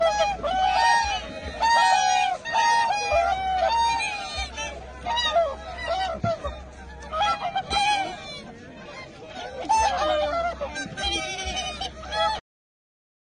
水边一群大天鹅引颈鸣叫